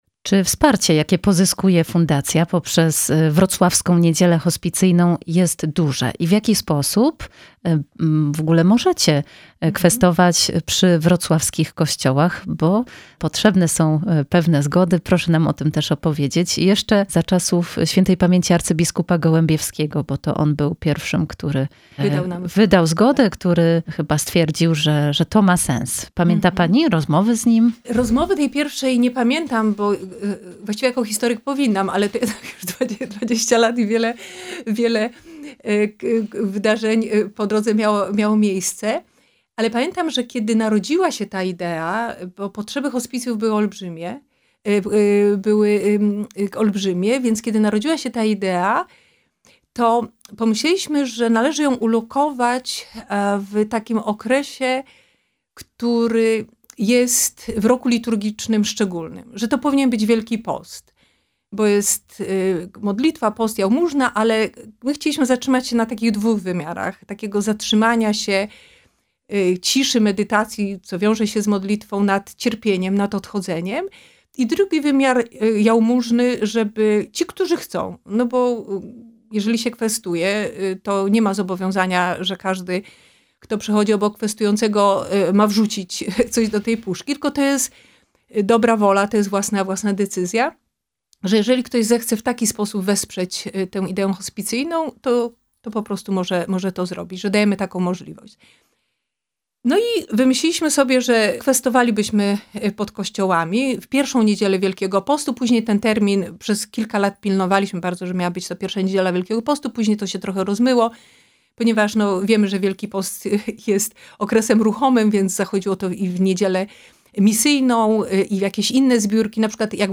Gościem Radia Rodzina jest